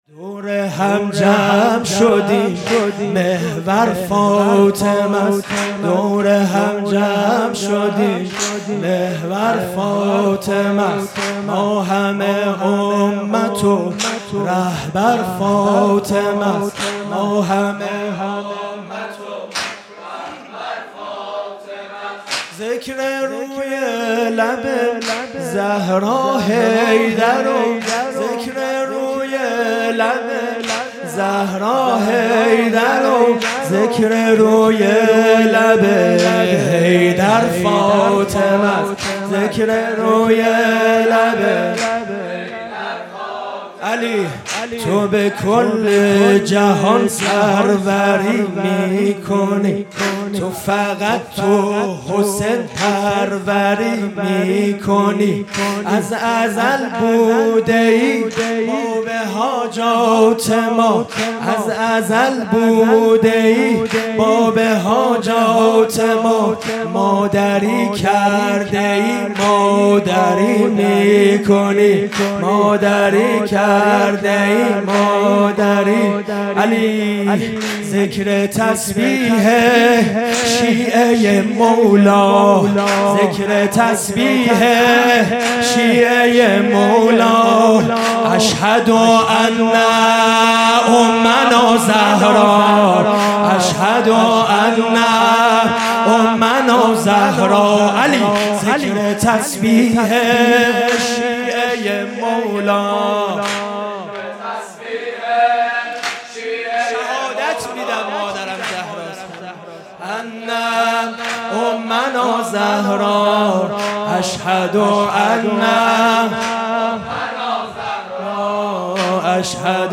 خیمه گاه - هیئت بچه های فاطمه (س) - واحد | دور هم جمع شدیم، محور فاطمس | 16 دی 1400
فاطمیه 1443 | شب دوم